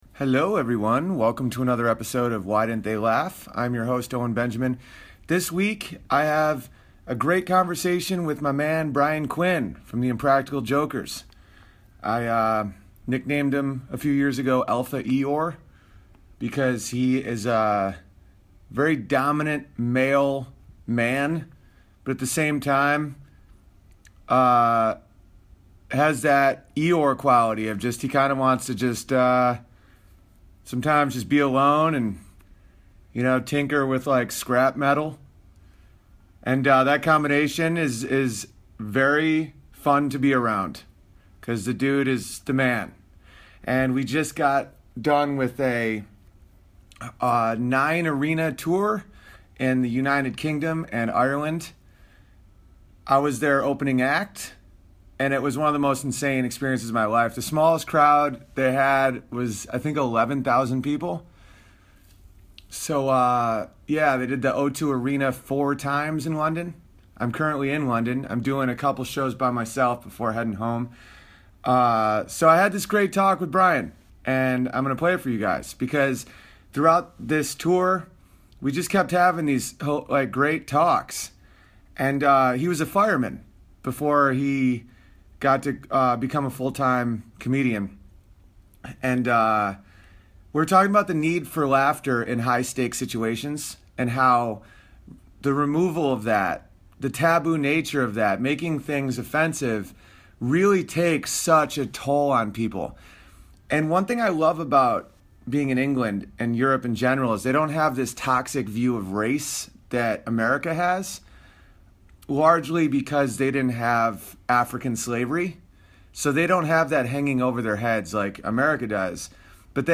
Floating down the thames river in England Brian and I discuss the role of comedy in fire fighting, what makes a great leader, and our drunken night with Neville long bottom.